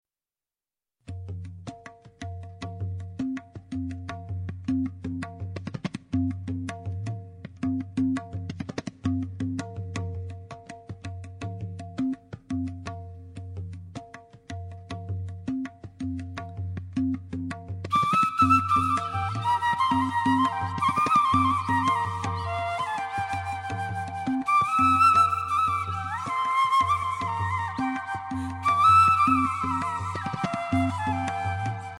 Ideal for relaxation and/or meditation